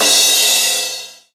DJP_PERC_ (5).wav